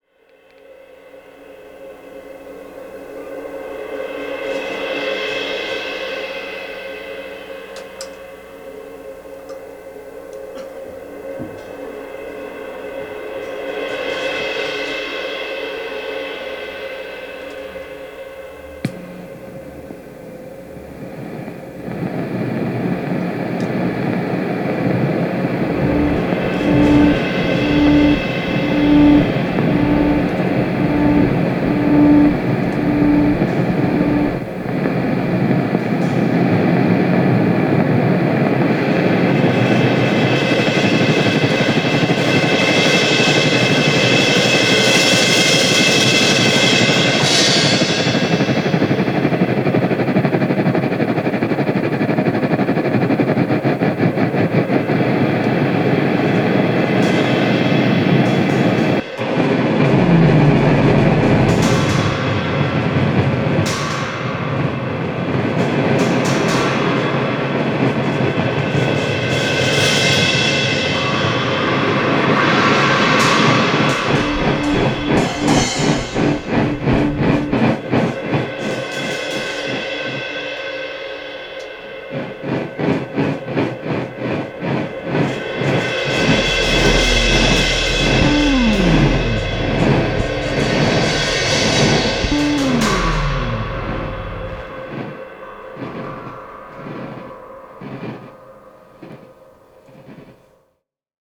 on bass
on drums
guitars and synthetizers
All wrapped up with a really nice jazzy vibe.